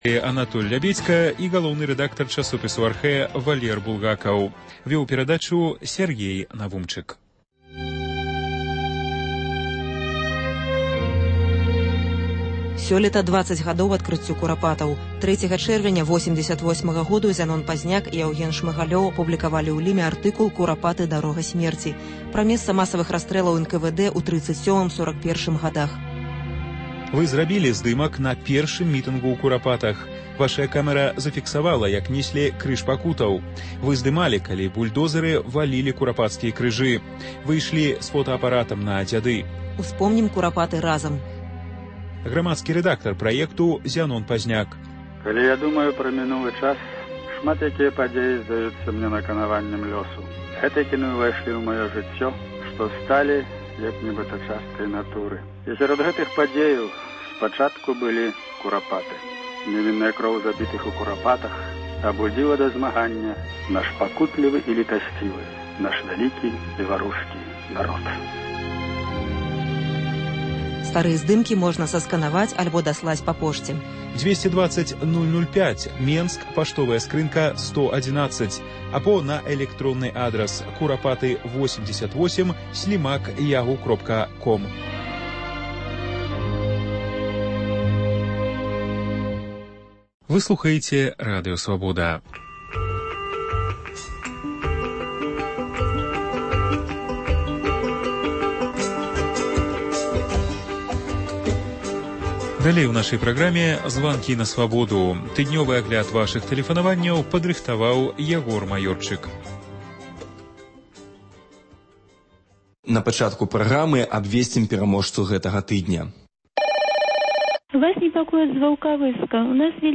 Агляд тэлефанаваньняў за тыдзень.